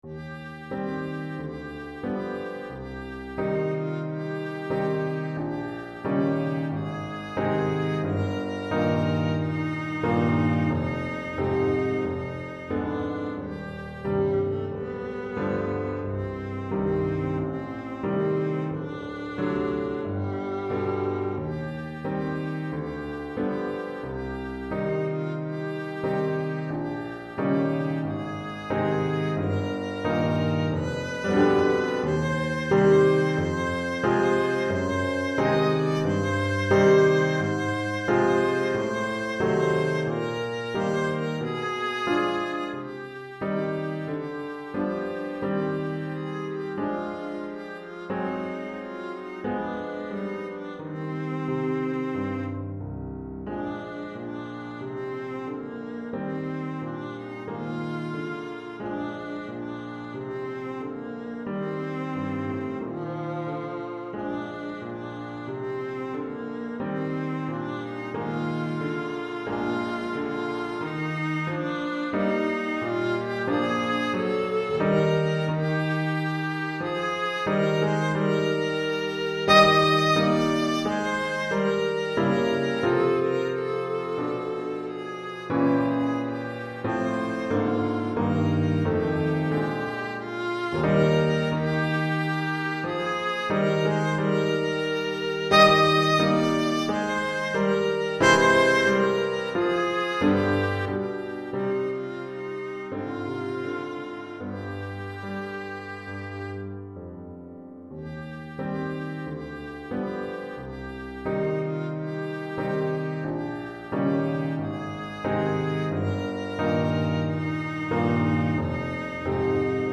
Répertoire pour Alto - Alto et Piano, en vente chez LMI - Suivi d'expédition, satisfait ou remboursé, catalogue de 300 000 partitions